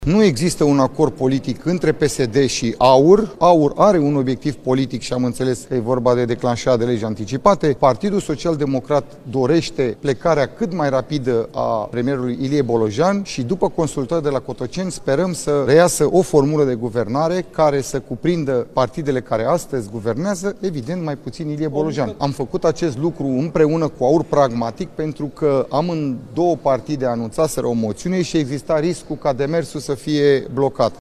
Liderul senatorilor PSD, Daniel Zamfir: „Nu există un acord politic între PSD și AUR”